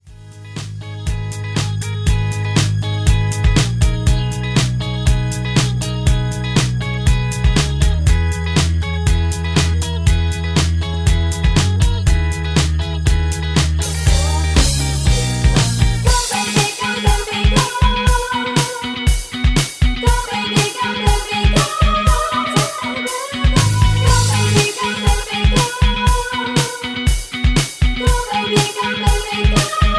G#m
MPEG 1 Layer 3 (Stereo)
Backing track Karaoke
Pop, Disco, 2000s